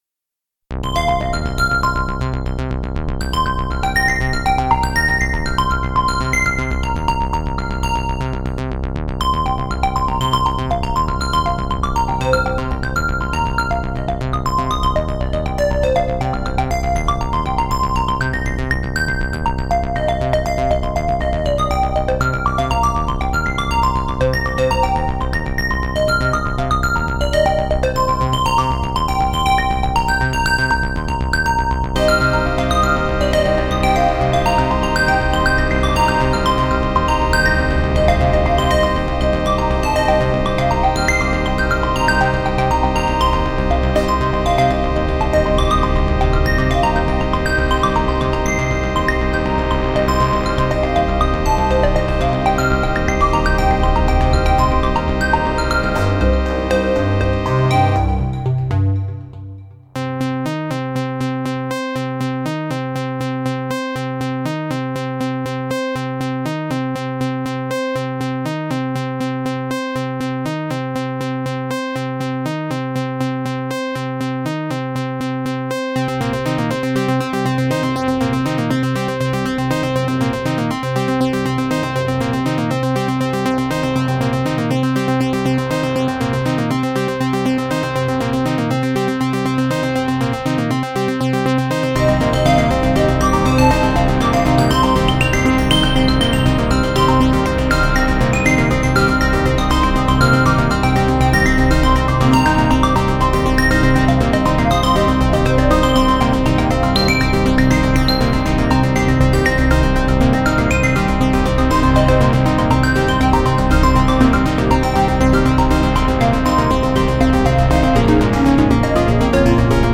Mit dem Syn’X 2 stellt XILS-Lab aus Grenoble eine neue Version der auf dem Elka Synthex Synth basierenden Software vor. Neben einigen Bugfixes, wurden die klanglichen Möglichkeiten der Software weiter vergrößert, sodas es sich eigentlich um einen eigenständigen Synthesizer handelt, der die Vintage Sounds des Elka, mit den Möglichkeiten heutiger Technik verbindet.